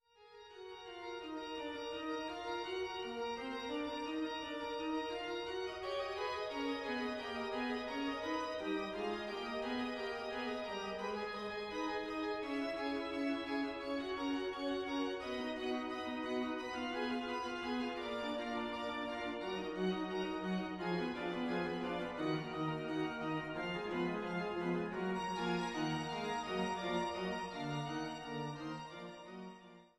Trost-Orgel in Altenburg